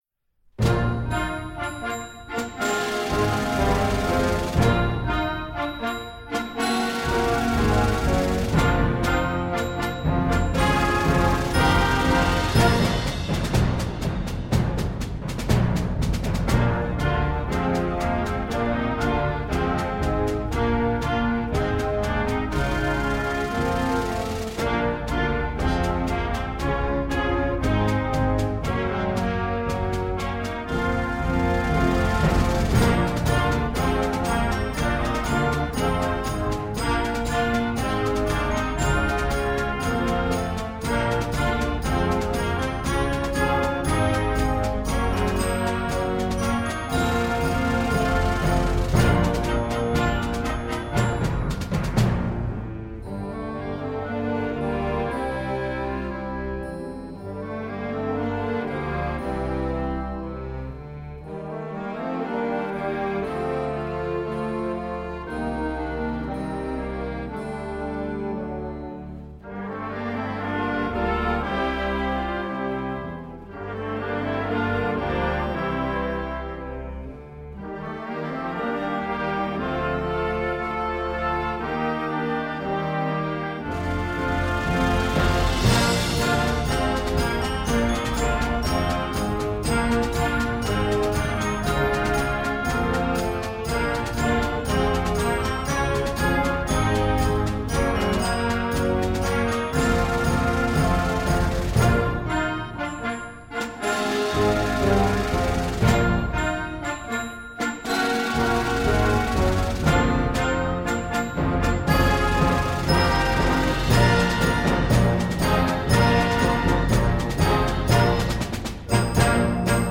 Gattung: Jugendwerk
23 x 30,5 cm Besetzung: Blasorchester PDF